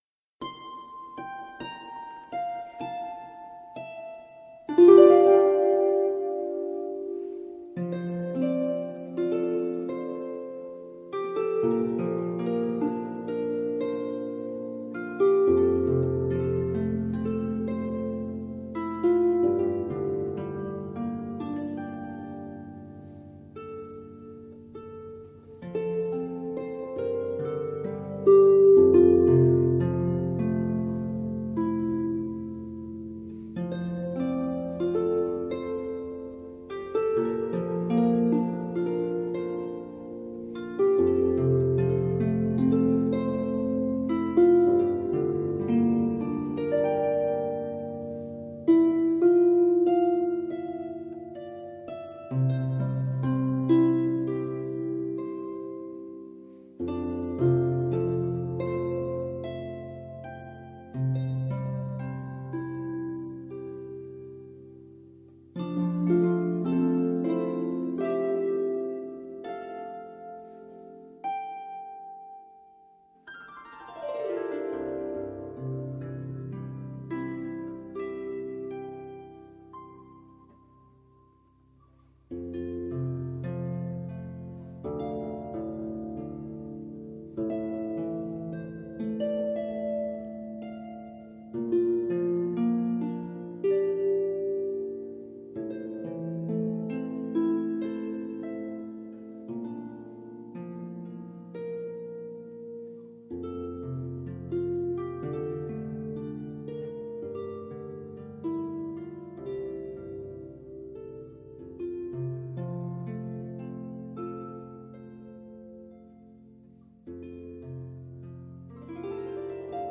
A collection of lullabies
blend of two harps, flute and cello.